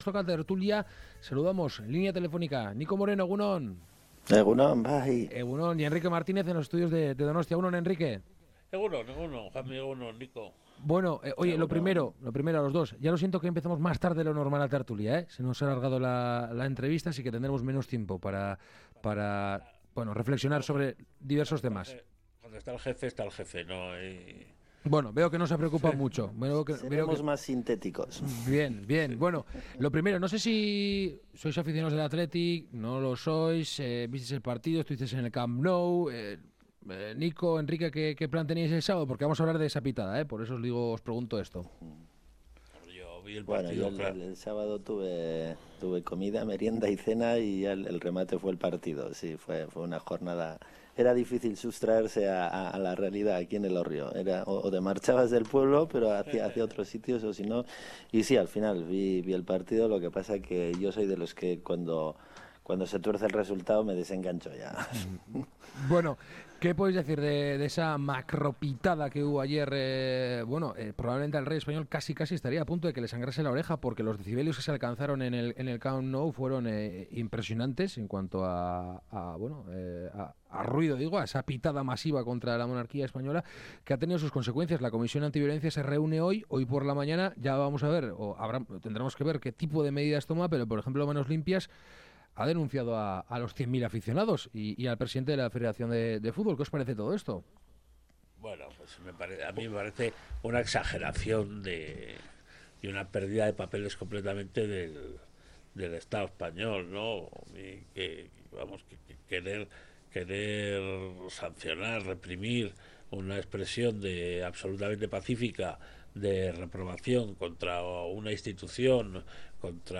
La tertulia de Kalegorrian
Charlamos y debatimos sobre algunas de las noticias más comentadas de la semana con nuestros colaboradores habituales.